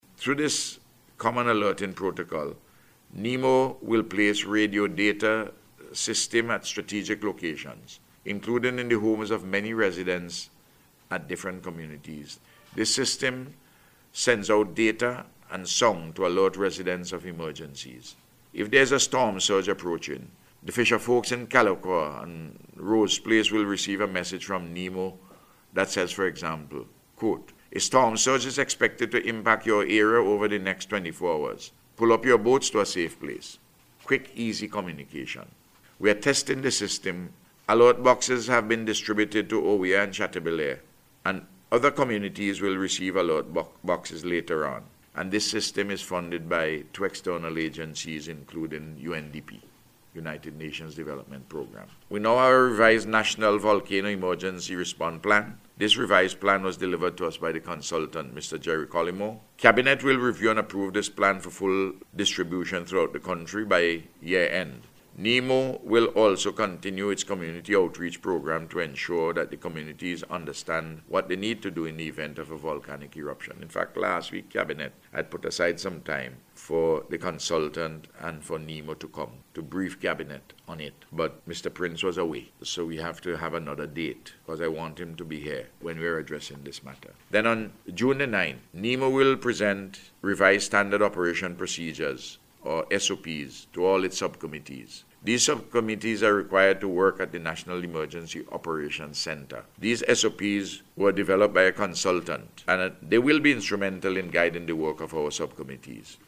In a Radio Address to mark the start of the Atlantic Hurricane Season yesterday, the Prime Minister said the National Emergency Management Organization is continuing to implement policies and strategies to build resilience against disasters.